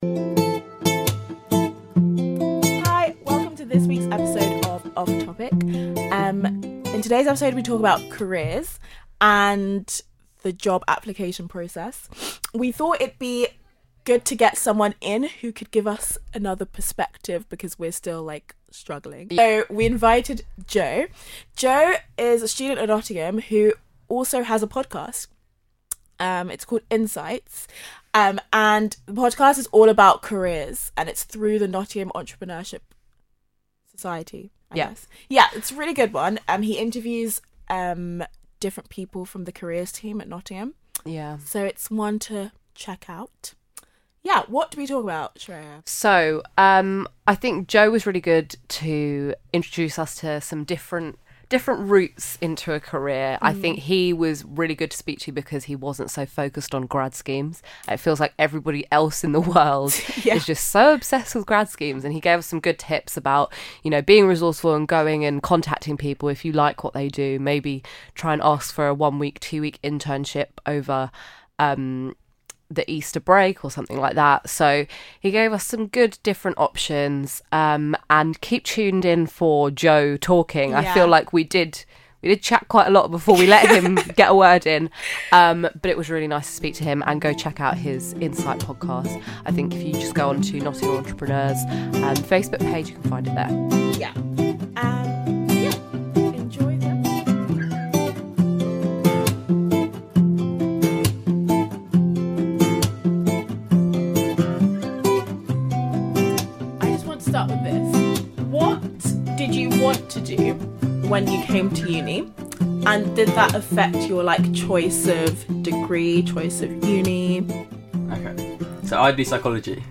This week's episode features a guest ! The 3 of us chat all things careers, entrepreneurship and alternate routes to securing a grad job.